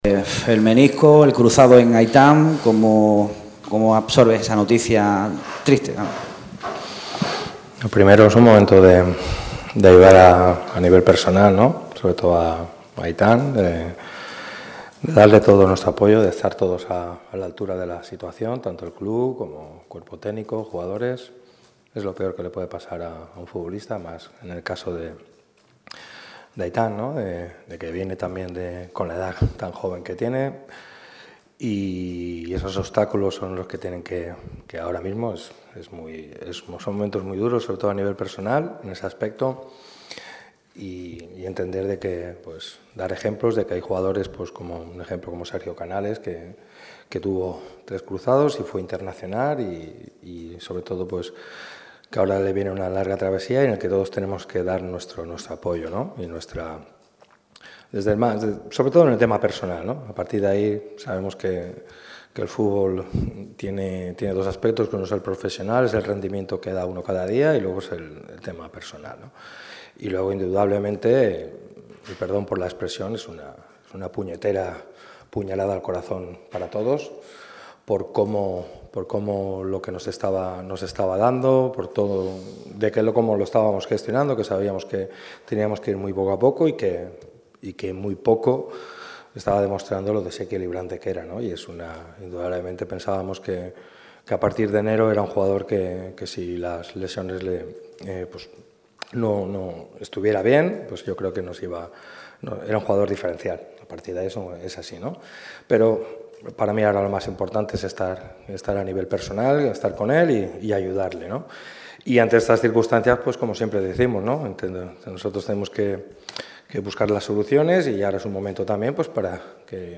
El técnico malaguista ha comparecido ante los medios en la previa del duelo que enfrentará a los boquerones contra el Alcoyano mañana a las 18:00 horas en La Rosaleda. Pellicer habla sobre la desgraciada lesion de Haitam, que le mantendrá fuera de los terrenos de juego muchos meses de nuevo y anañiza el duelo ante los del Alcoy, un partido «importantísimo».